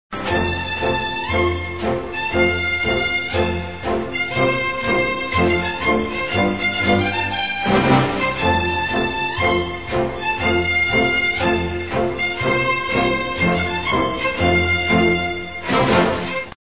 signature tune